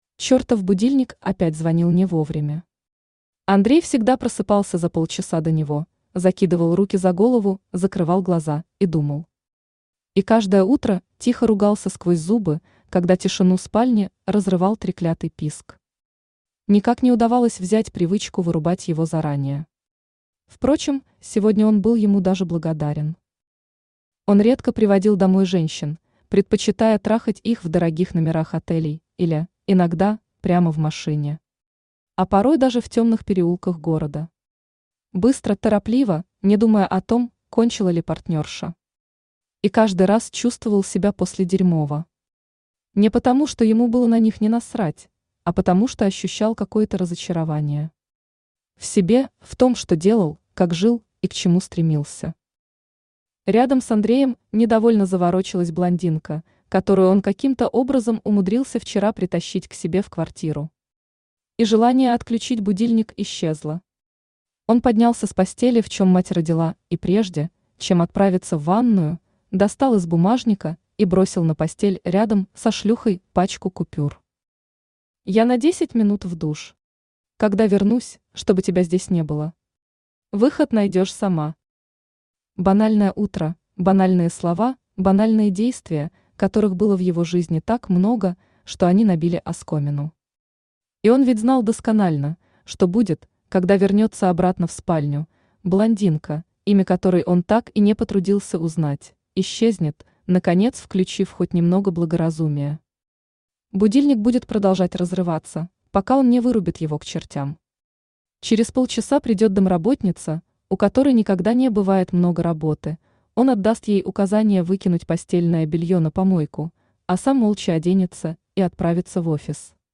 Аудиокнига В постели с боссом | Библиотека аудиокниг
Aудиокнига В постели с боссом Автор Тати Блэк Читает аудиокнигу Авточтец ЛитРес.